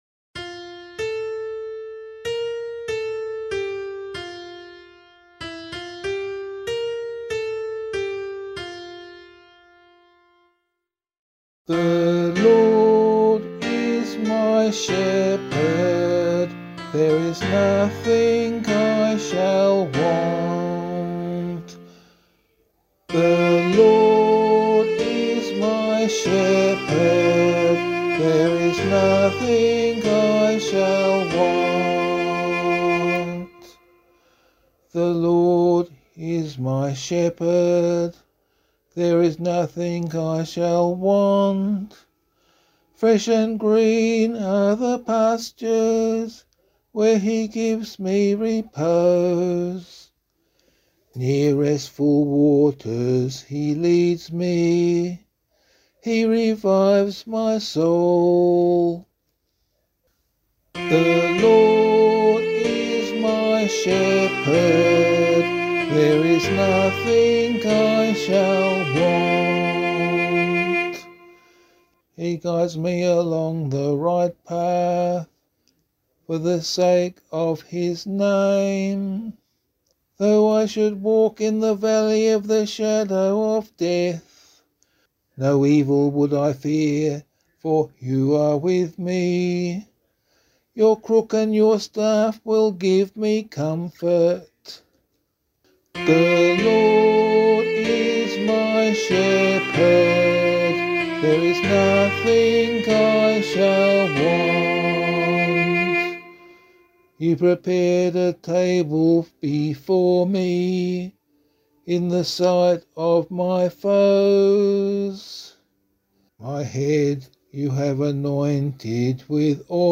Demo is transposed to E♭ major.